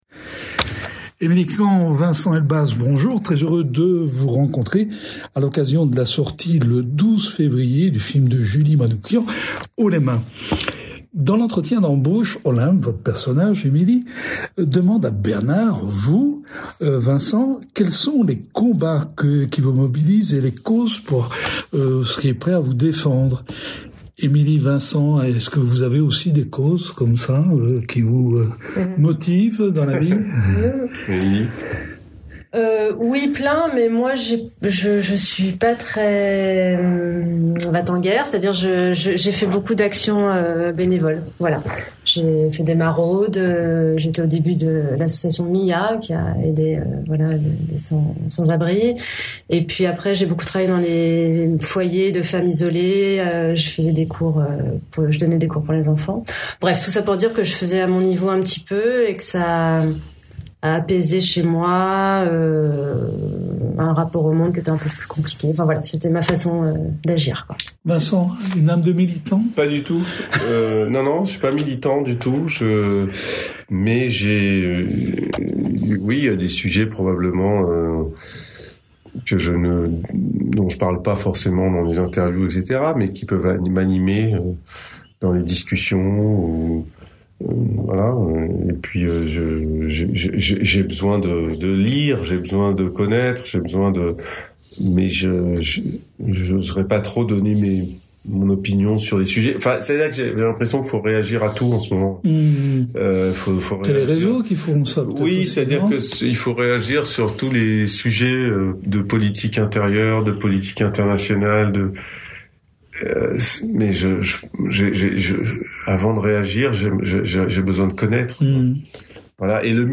Rencontre avec les deux têtes d’affiche.«